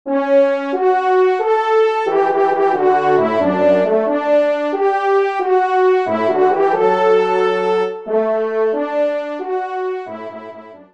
20 sonneries pour Cors et Trompes de chasse